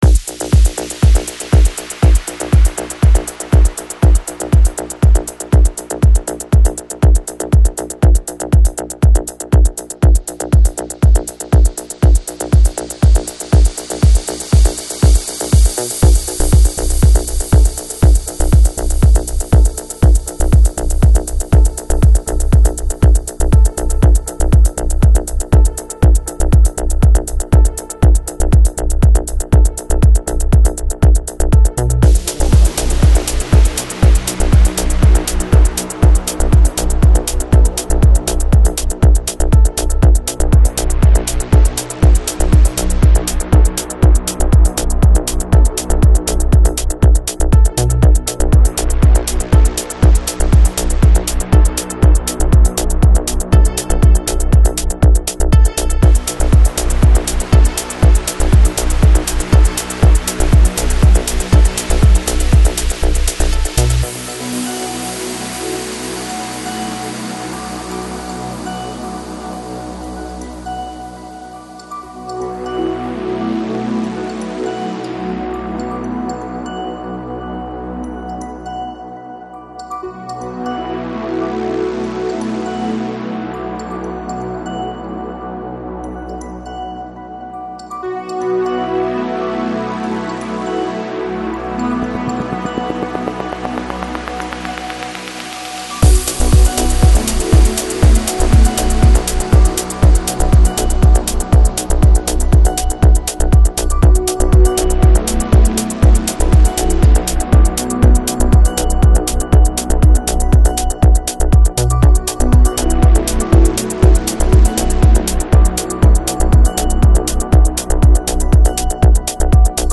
Жанр: Progressive House, Tech House